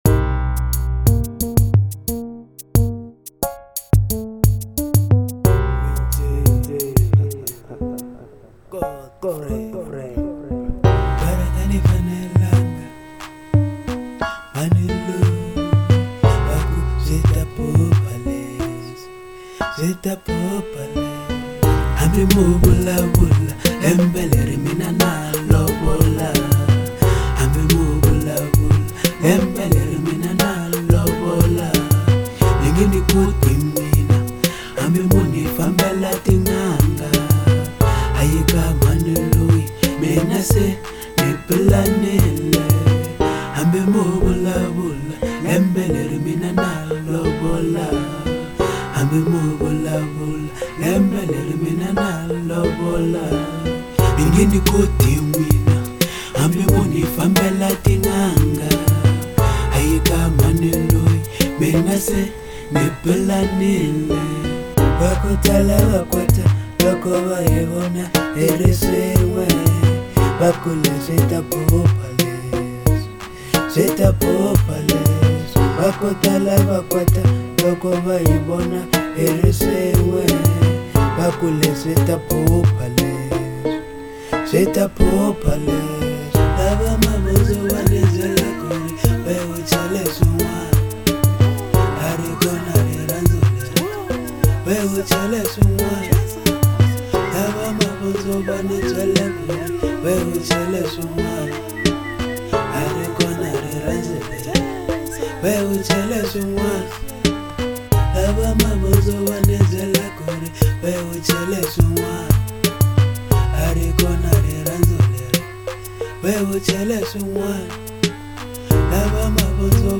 04:08 Genre : Hip Hop Size